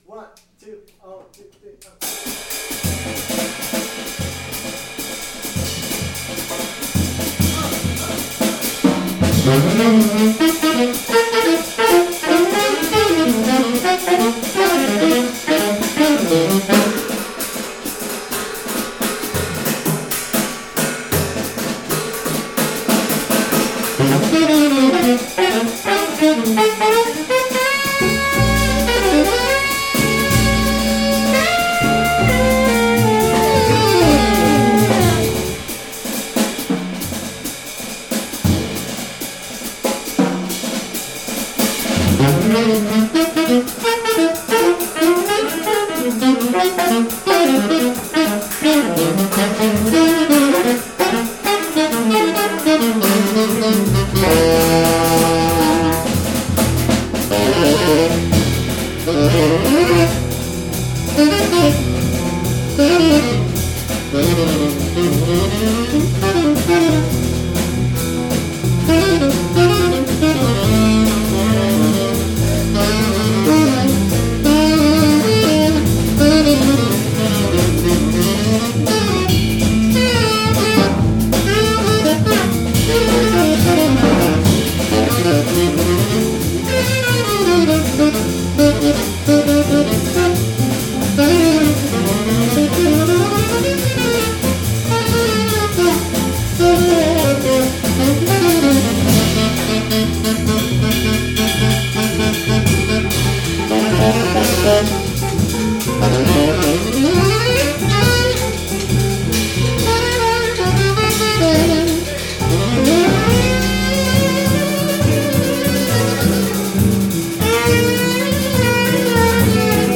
Tenor Saxophone
Alto-Soprano Saxophones
Bass
Drums
Guitar